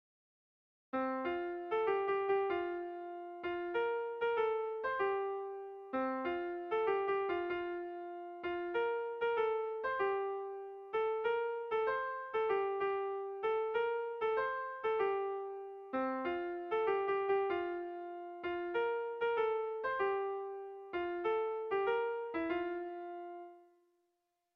Sentimenduzkoa
Gipuzkoa < Basque Country
Zortziko txikia (hg) / Lau puntuko txikia (ip)
AABA2